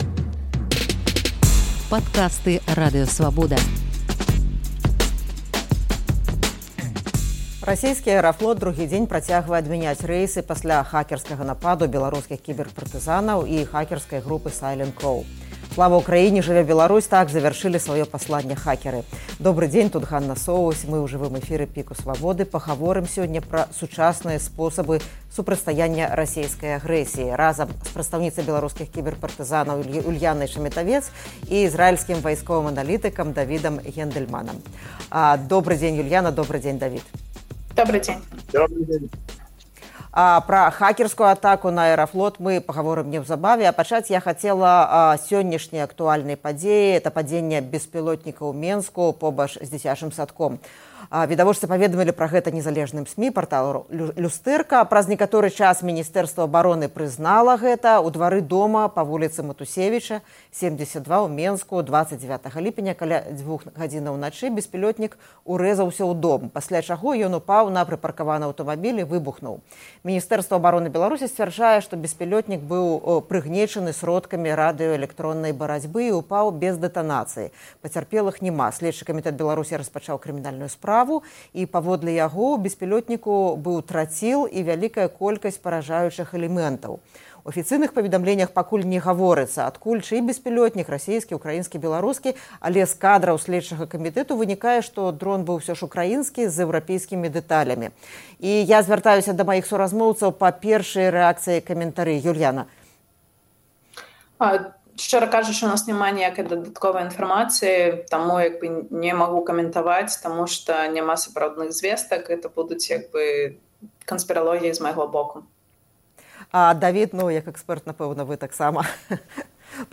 У жывым эфіры «ПіКа» Свабоды